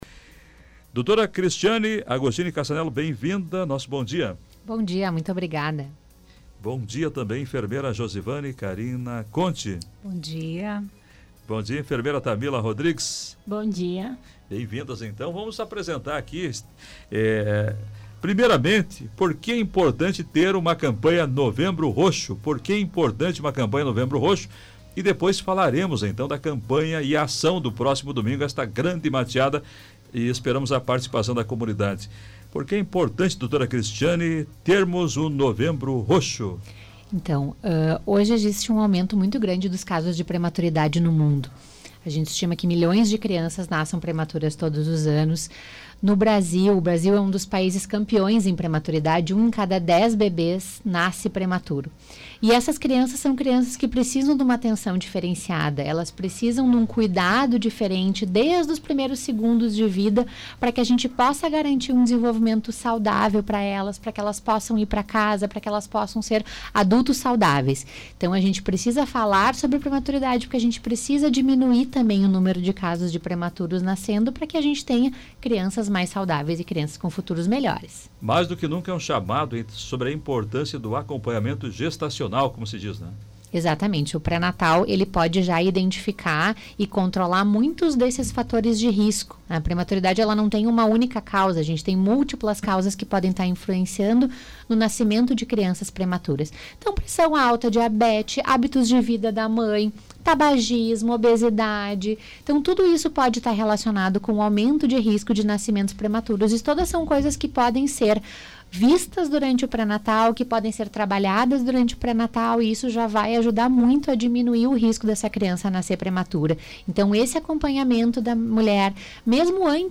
ENTREVISTA-HSVP-18-11.mp3